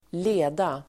Uttal: [²l'e:da]